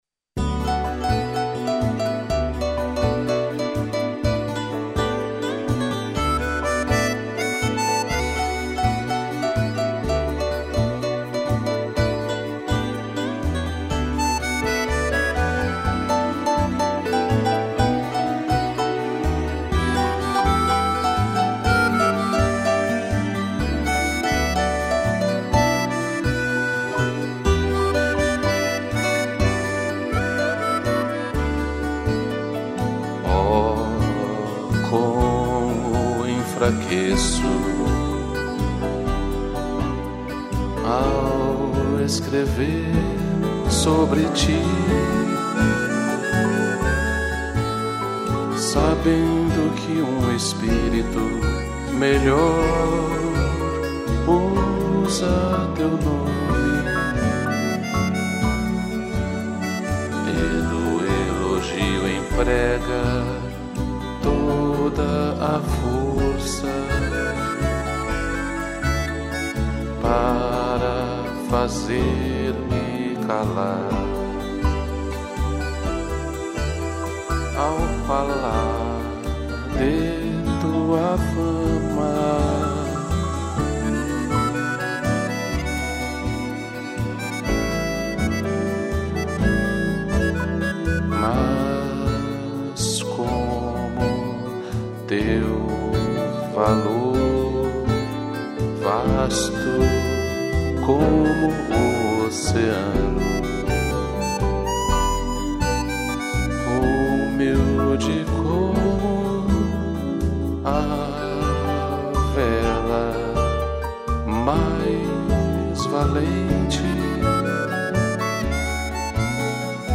piano e gaita